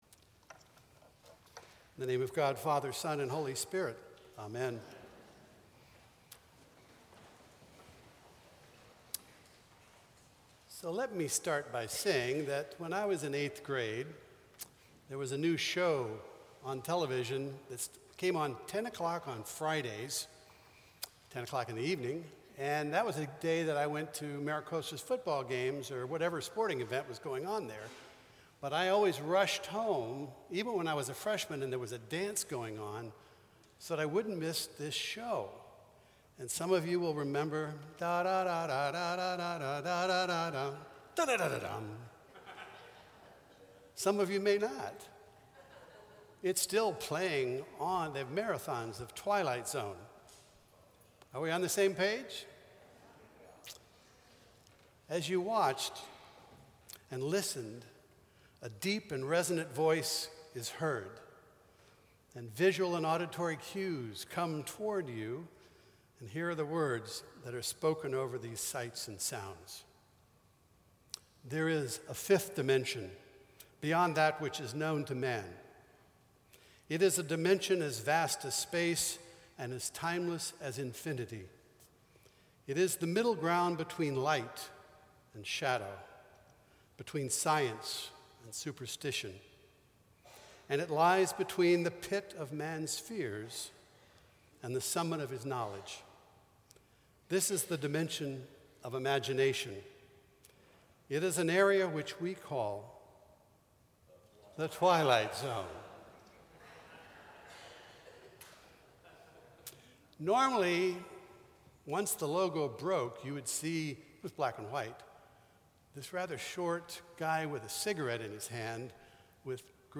Sermons from St. Cross Episcopal Church Second Sunday in Lent Feb 23 2016 | 00:13:33 1x 00:00 / 00:13:33 Subscribe Share Apple Podcasts Spotify Overcast RSS Feed Share Link Embed